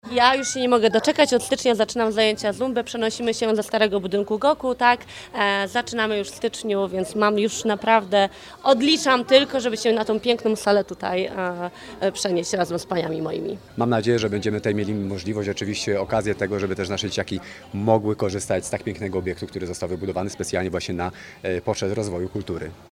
– To bardzo ważne miejsce dla nas mieszkańców – podkreślają mieszkanki i mieszkańcy.